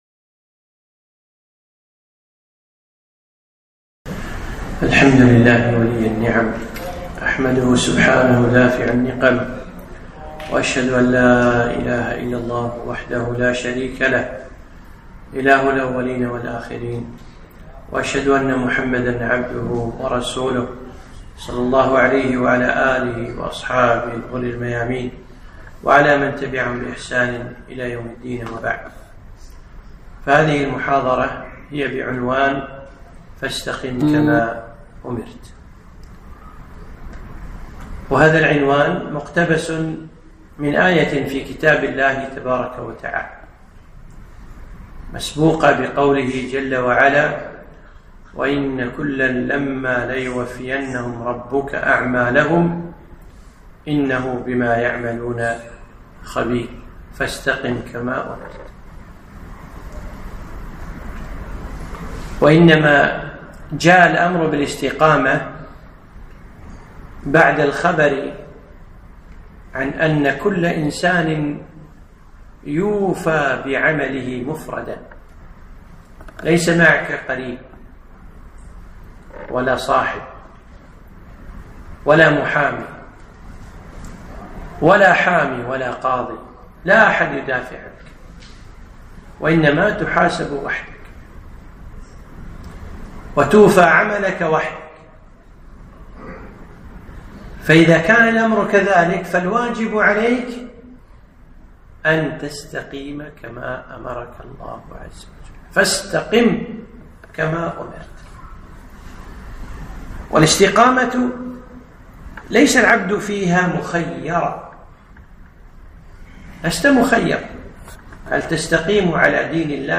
محاضرة - فاستقم كما أمرت